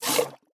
哞菇：被挤奶
玩家使用碗对哞菇挤奶时随机播放这些音效
Minecraft_mooshroom_milk2.mp3